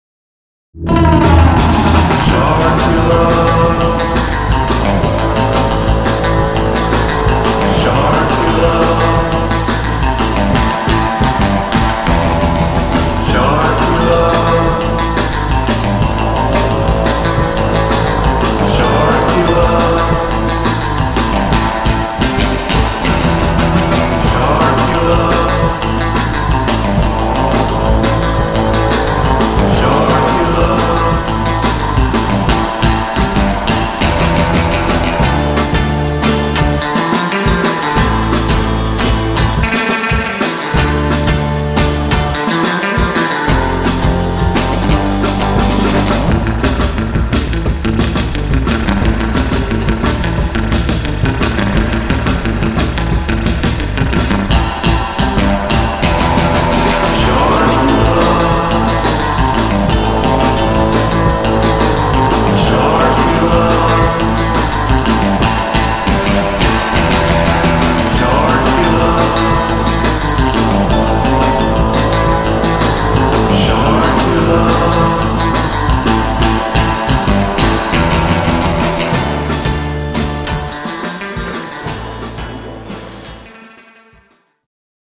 catchy, 1960s-style, surf-guitar piece